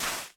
Minecraft Version Minecraft Version snapshot Latest Release | Latest Snapshot snapshot / assets / minecraft / sounds / block / suspicious_sand / place1.ogg Compare With Compare With Latest Release | Latest Snapshot